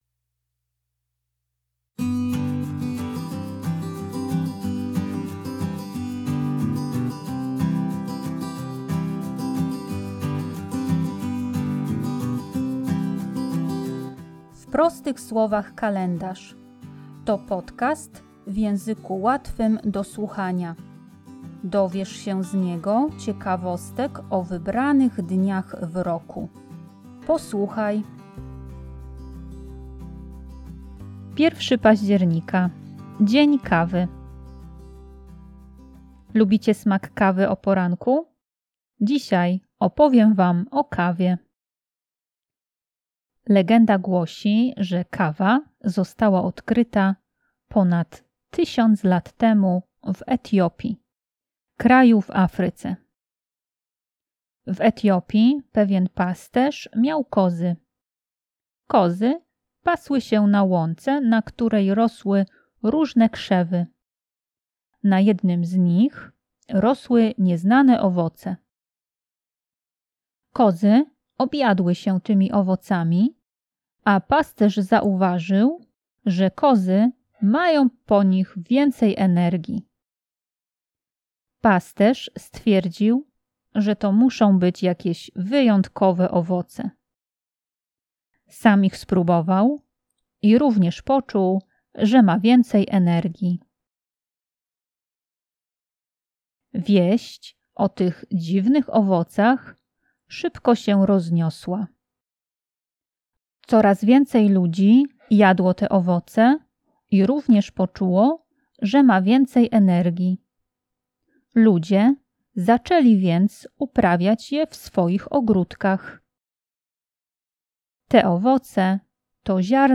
Tekst i lektorka